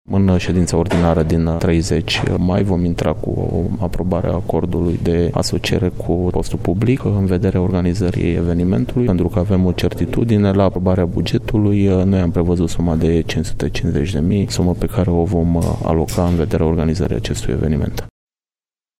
În aceeași ședință va fi votată și alocarea bugetară din partea Primăriei pentru organizarea evenimentului, după cum a precizat viceprimarul municipiului Brașov, Costel Mihai: